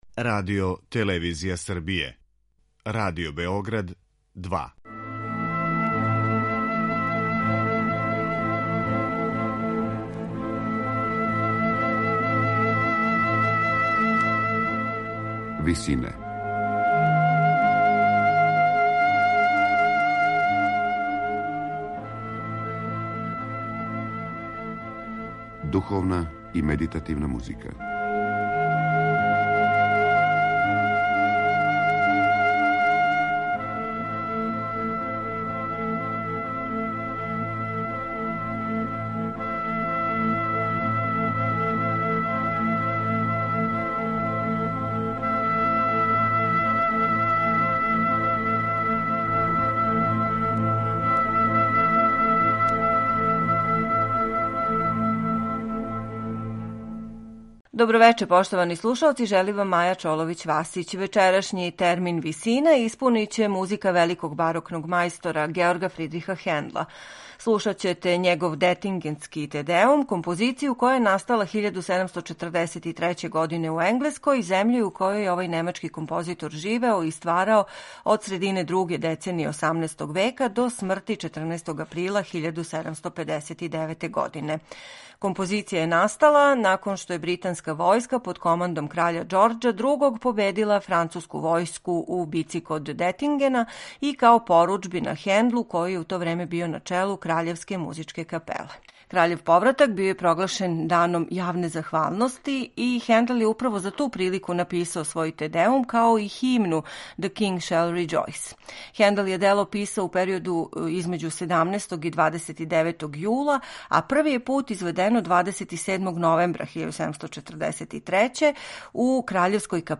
Духовна музика Георга Фирдриха Хендла